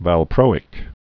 (văl-prōĭk)